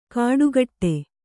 ♪ kāḍugaṭṭe